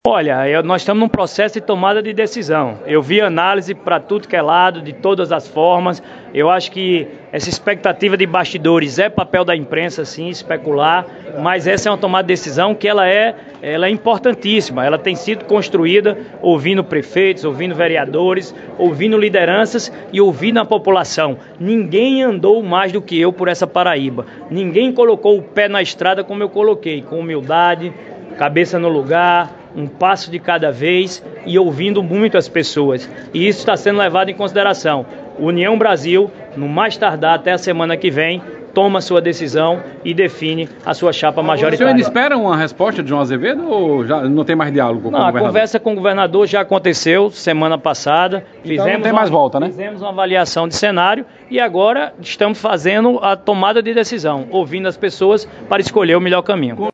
Na oportunidade, ao falar com à imprensa presente, Efraim ‘deu sinais’ em já estar distante do governador João Azevêdo, que não decidiu da escolha do seu candidato a senador na sua chapa.
Ouça a fala de Efraim Filho abaixo.